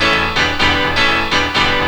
PIANO LOO0AL.wav